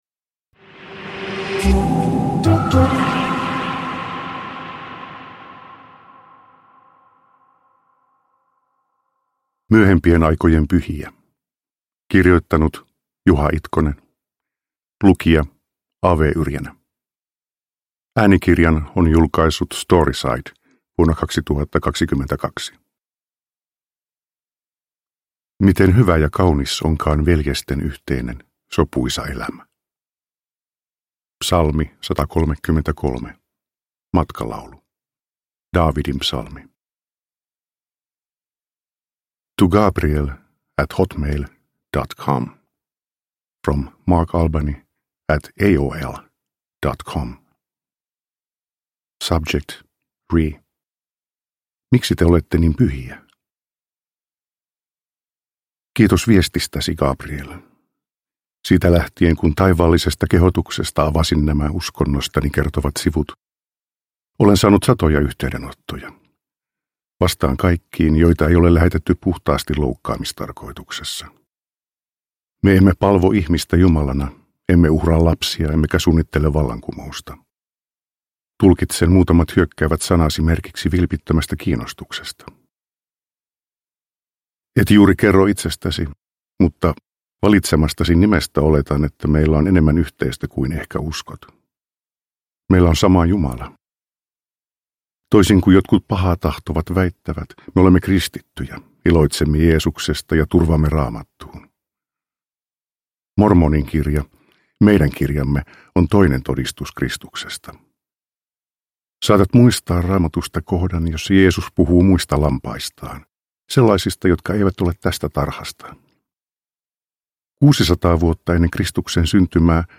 Uppläsare: A.W. Yrjänä